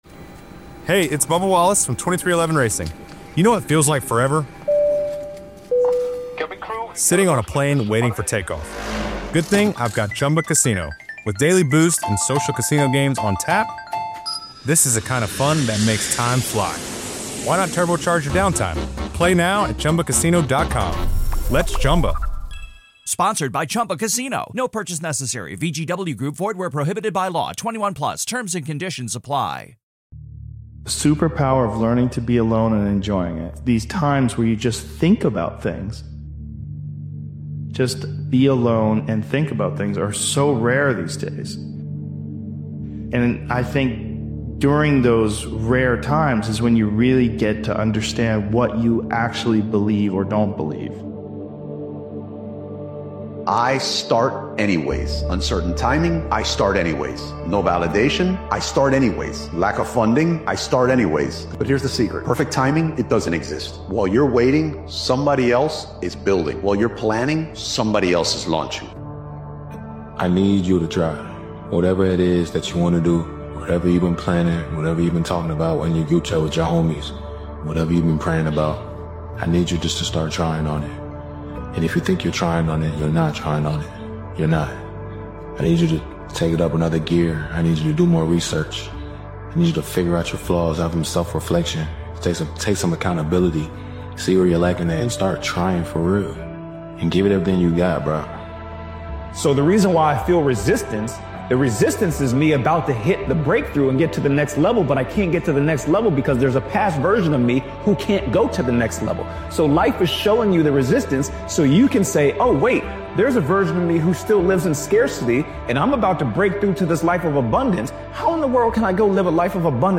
This powerful motivational speeches compilation is for those who refuse to blend in or settle for a life beneath their potential. It’s a reminder that comfort creates mediocrity, while focus and persistence create separation.